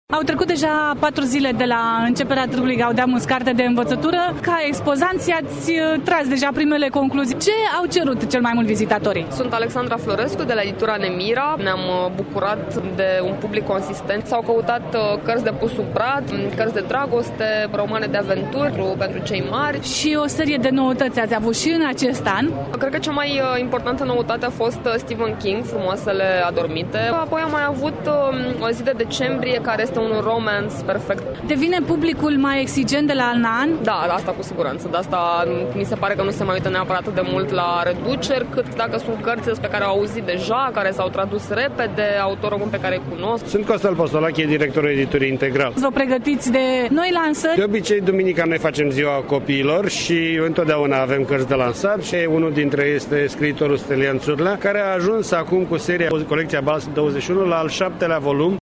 EXPOZANTI.mp3